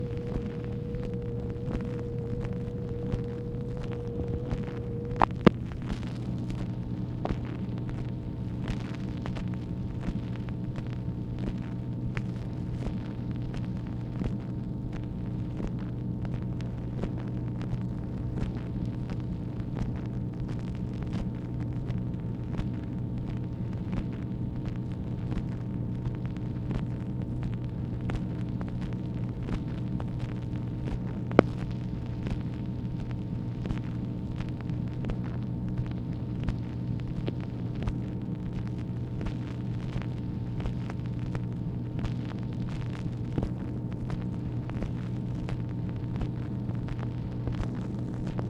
MACHINE NOISE, August 4, 1964